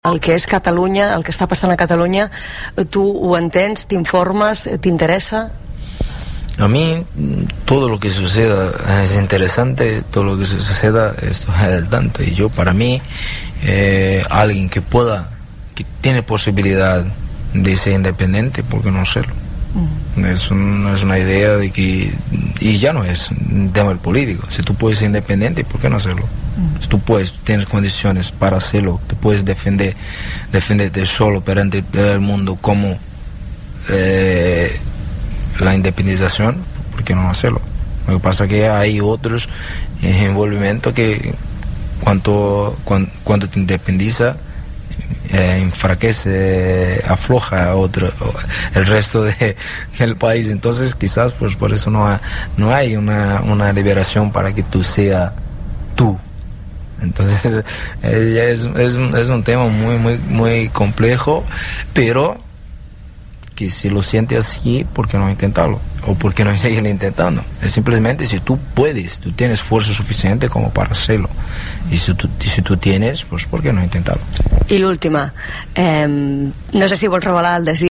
El jugador del FC Barcelona Dani Alves s'ha mostrat favorable a la independència. En una entrevista ahir al programa 'Primer toc' de Rac1, preguntat per la situació que viu actualment Catalunya, va explicar (